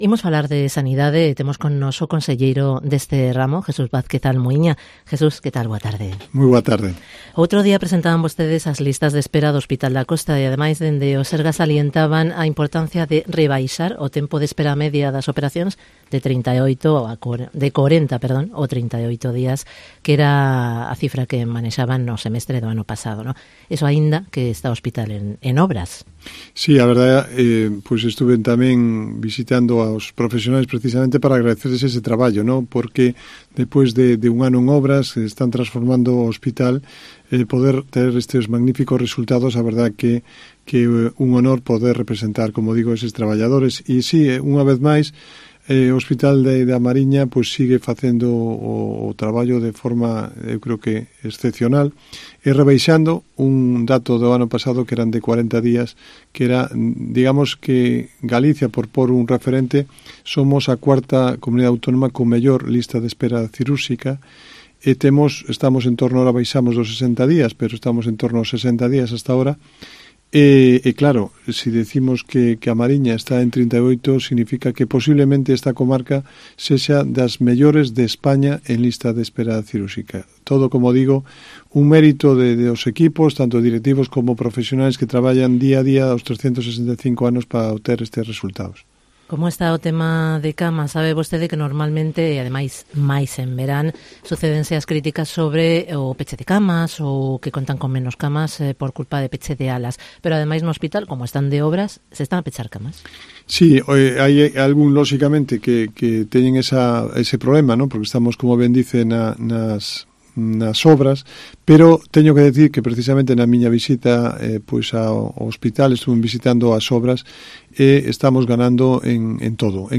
Entrevista con el conselleiro de Sanidad